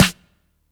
Track 08 - Snare OS.wav